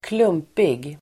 Uttal: [²kl'um:pig]